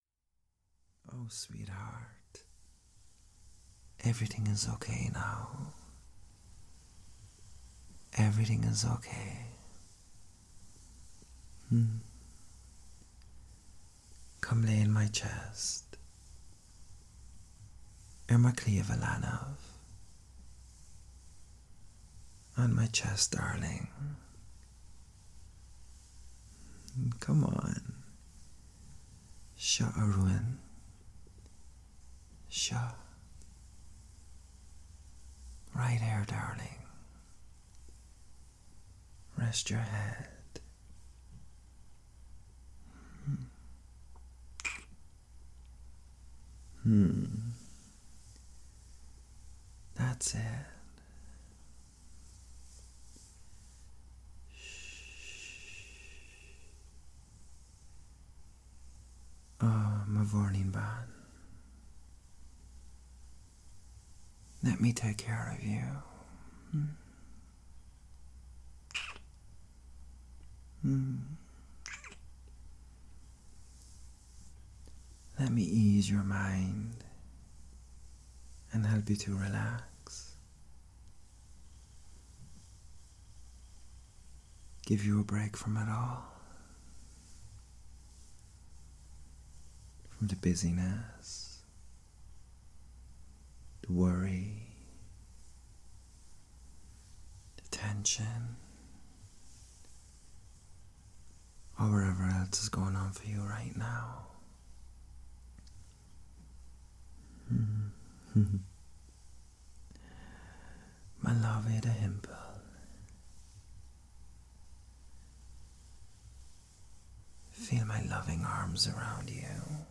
There may be some sound issues as-well but I'll fix those!HappyFriday
Downloads Download unnamed_piece_24_nov.mp3 Download unnamed_piece_24_nov.mp3 Content So this is a piece that can be used for sleep or for a lash of relaxing waves like There may be some sound issues as-well but I'll fix those!